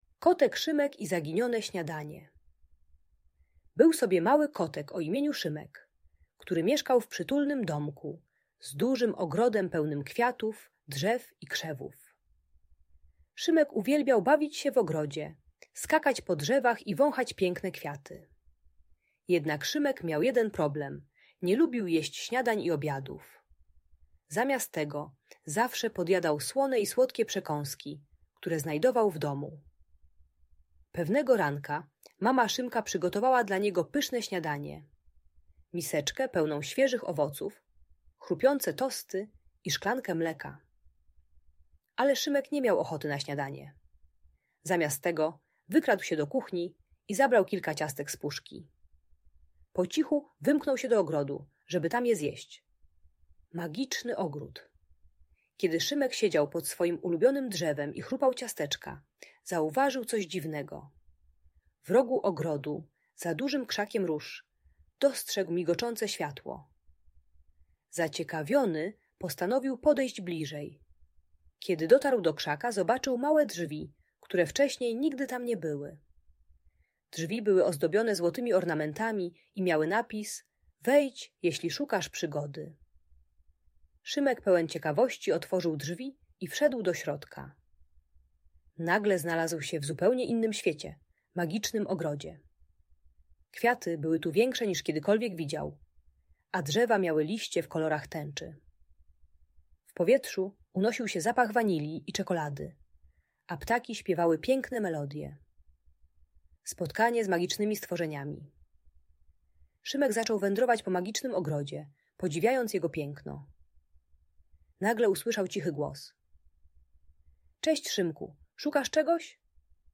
Opowieść o Kocie Szymku i Magicznym Ogrodzie - Audiobajka